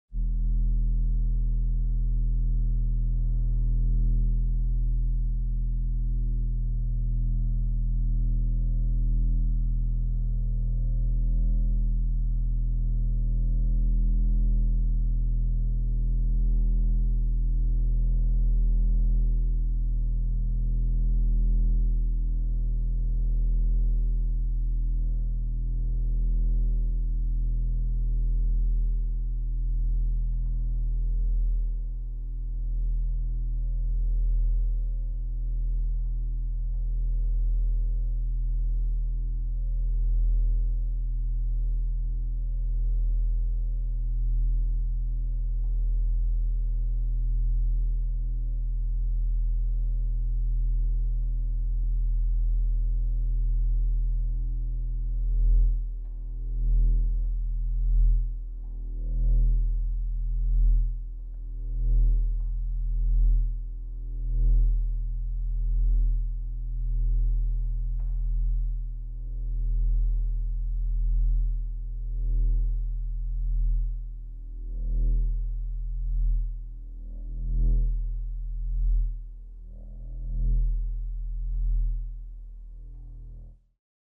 Звуки электричества
Тихий низкочастотный гул